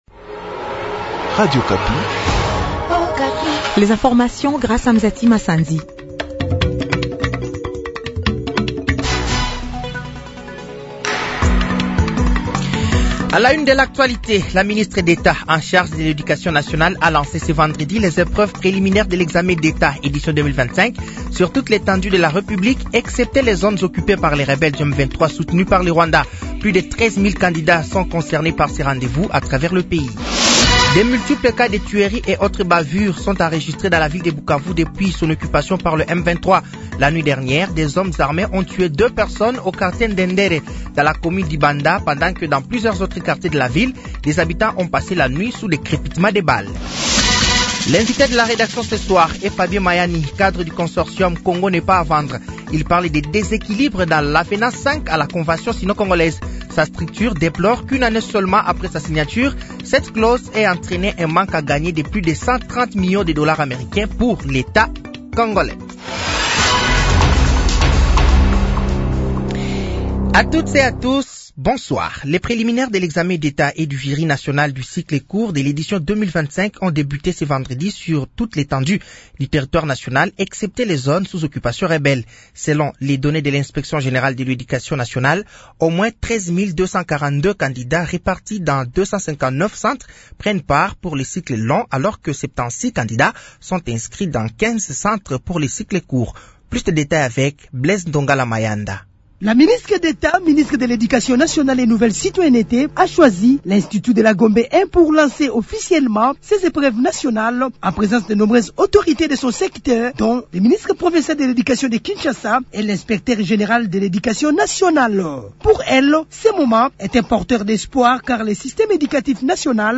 Journal français de 18h00 de ce vendredi 07 mars 2025